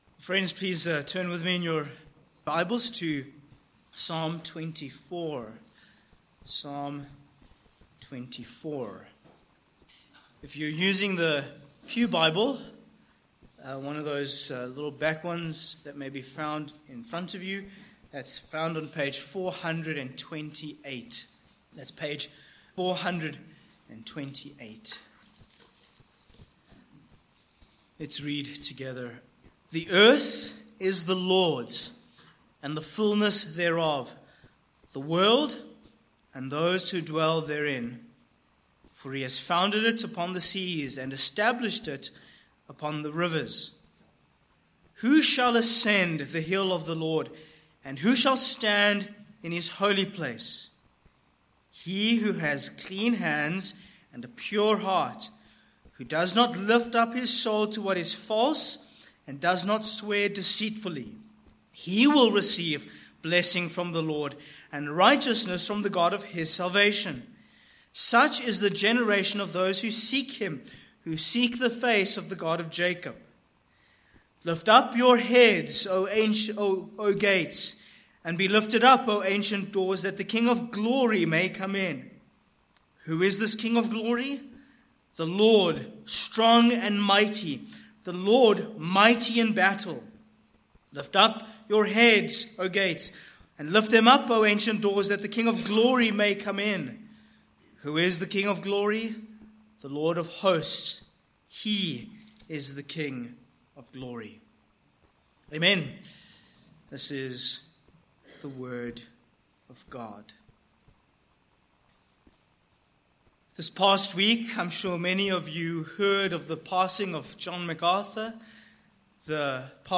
Sermon points: 1. The Creator God